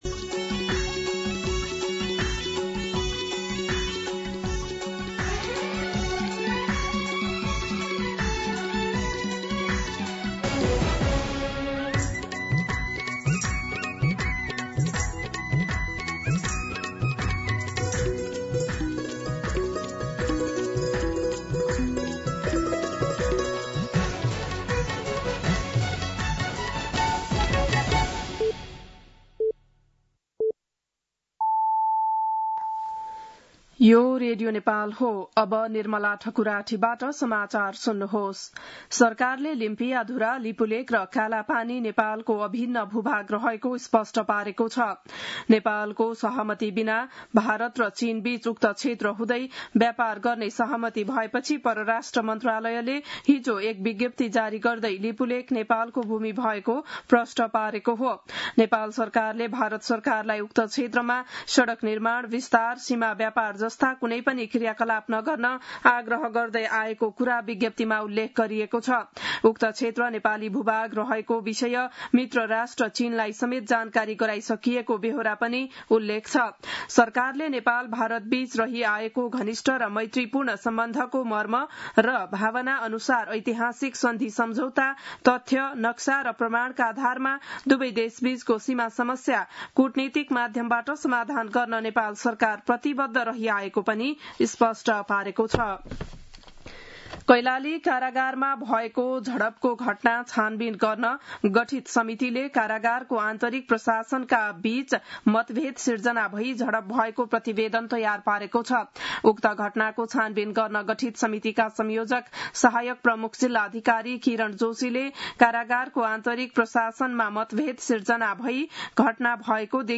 बिहान ११ बजेको नेपाली समाचार : ५ भदौ , २०८२